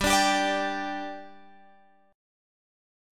G5 chord {15 17 17 x 15 15} chord